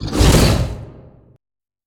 Sfx_creature_bruteshark_bite_01.ogg